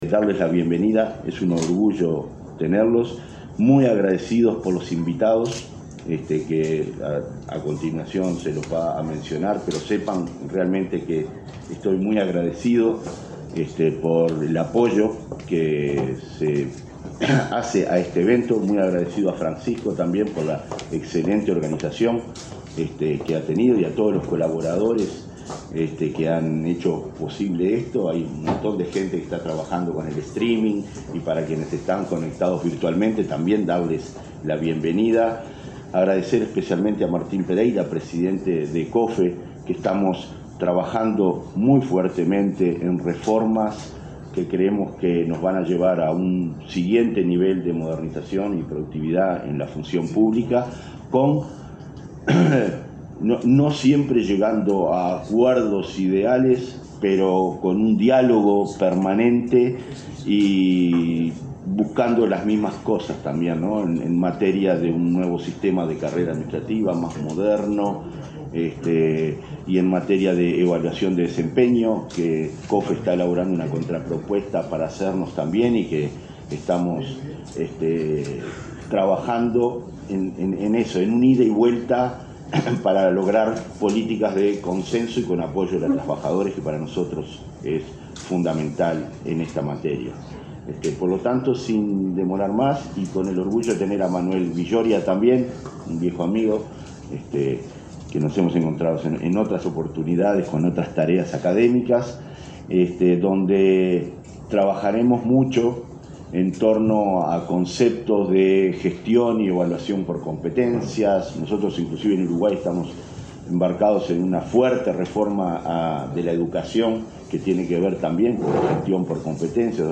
Palabras del director de la ONSC, Conrado Ramos
Este jueves 20, en Montevideo, el director de la Oficina Nacional del Servicio Civil (ONSC), Conrado Ramos, abrió la conferencia del Centro